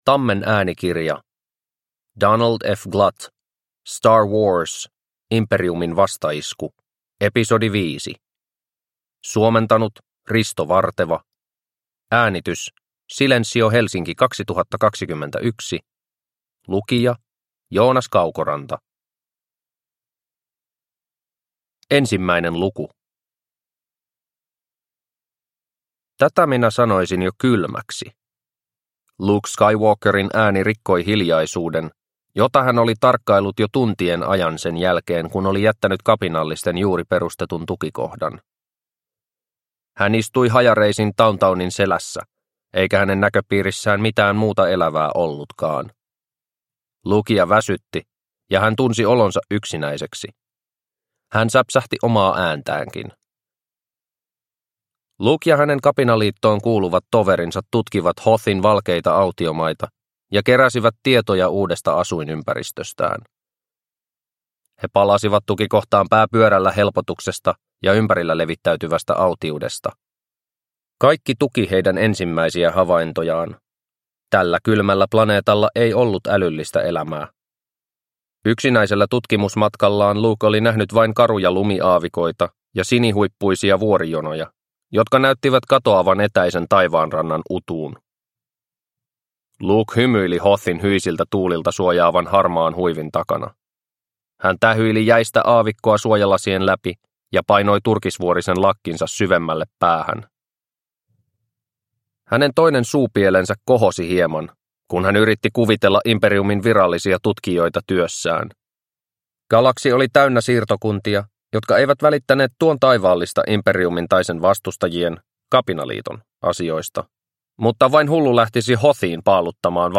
Star Wars. Imperiumin vastaisku – Ljudbok – Laddas ner